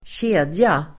Uttal: [²tj'e:dja]